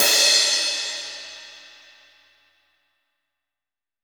OZ16CRASH2-S.WAV